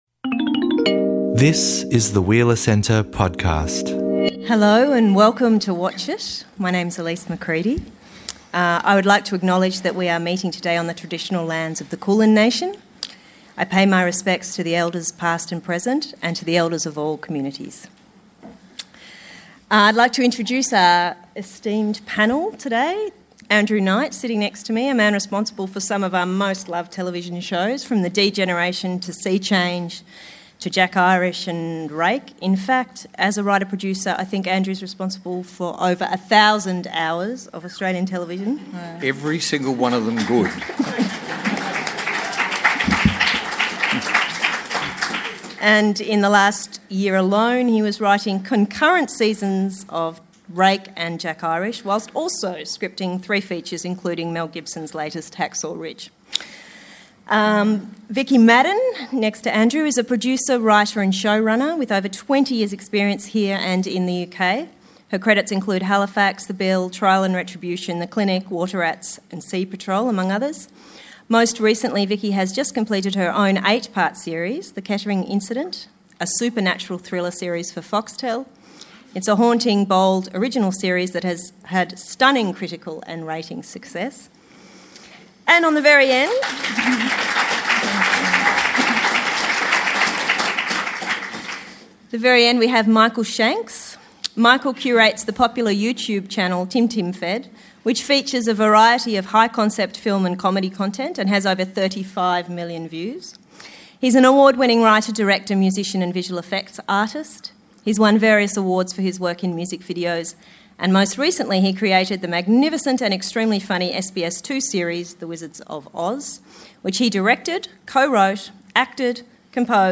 In this discussion, we’ll examine the relationships between viewers, writers and networks. How do screenwriters deal with the heightened attention of on-demand viewing? How do networks and platforms test new shows and talent?